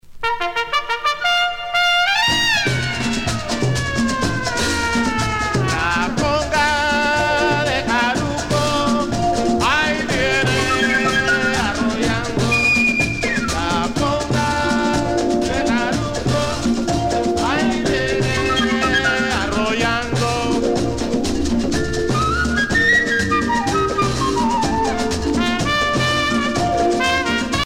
danse : conga